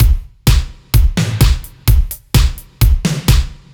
Index of /musicradar/french-house-chillout-samples/128bpm/Beats
FHC_BeatC_128-03.wav